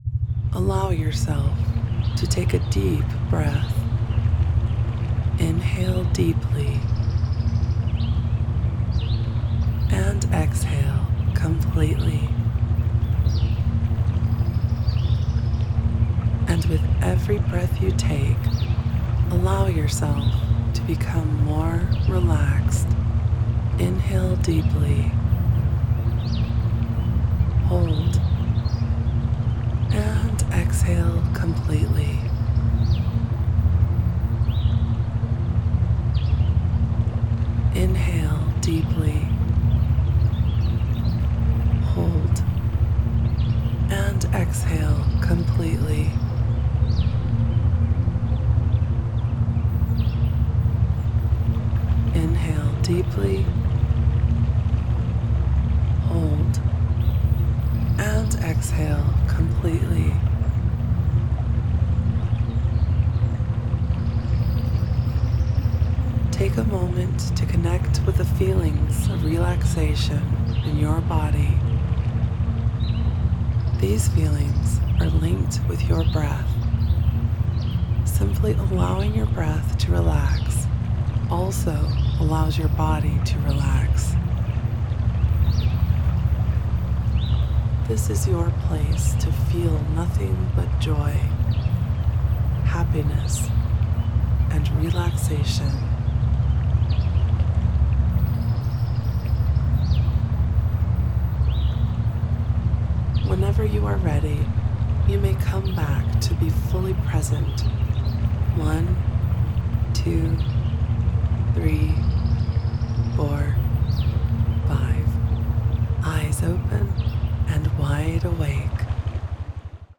2-minuted guided meditation
Alpha-Solo-2min-Guided_320kbps.mp3